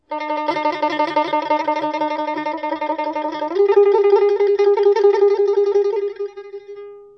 Ngón láy rền: là tăng cường động tác của ngón láy cho nhanh và nhiều hơn với sự phối hợp vê dây của tay phải.